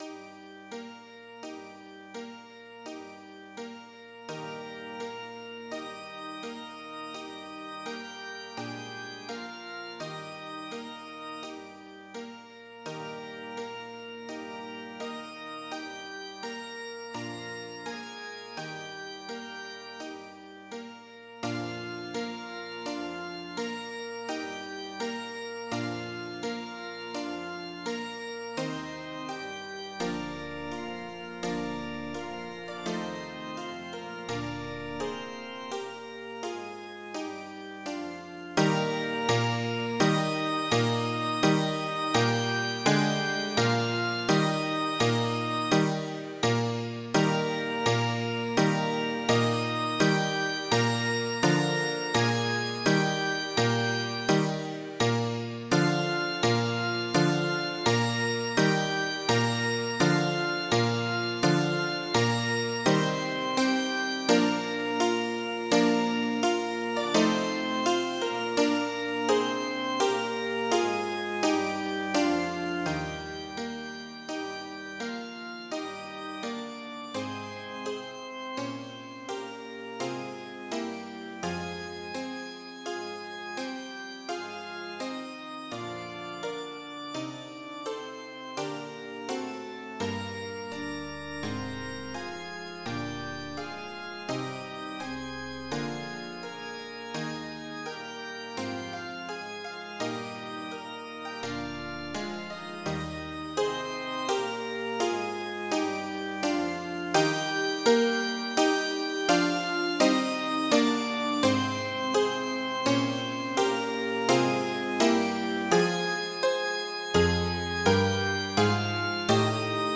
/ cdmania.iso / music / mozart / mozart2.mid ( .mp3 ) < prev next > MIDI Music File | 1996-04-15 | 30KB | 2 channels | 44,100 sample rate | 7 minutes, 42 seconds Type General MIDI